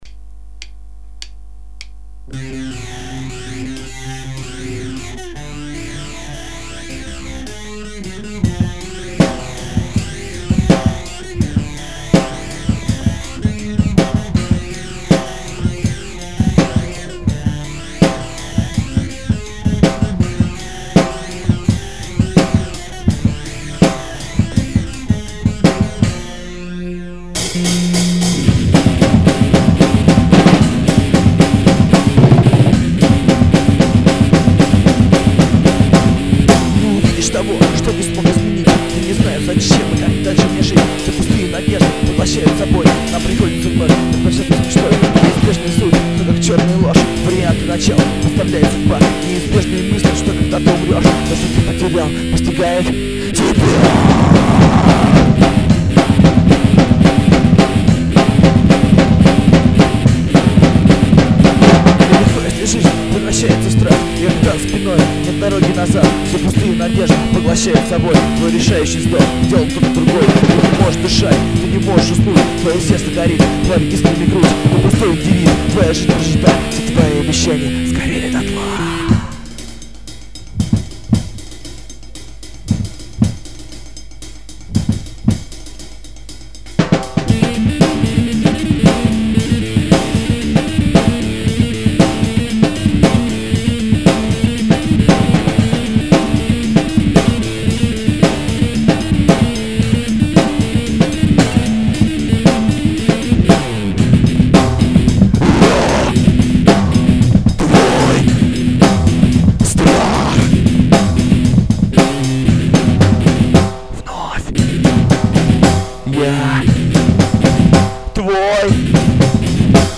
Качество только не оч, а текст хороший и музычка достойная )